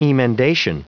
Prononciation du mot emendation en anglais (fichier audio)
Prononciation du mot : emendation